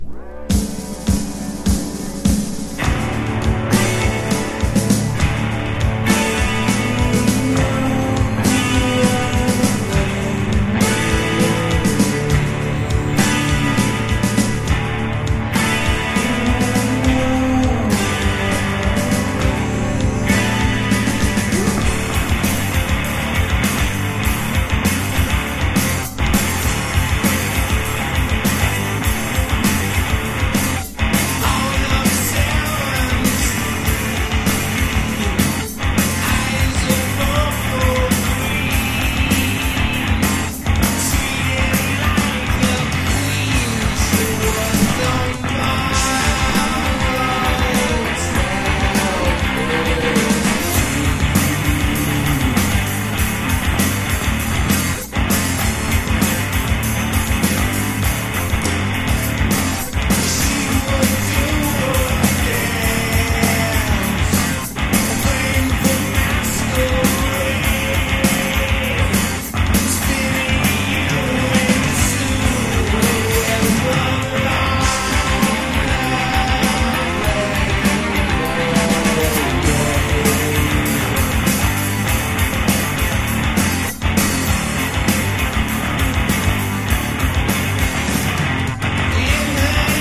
1. 90'S ROCK >
ALTERNATIVE / GRUNGE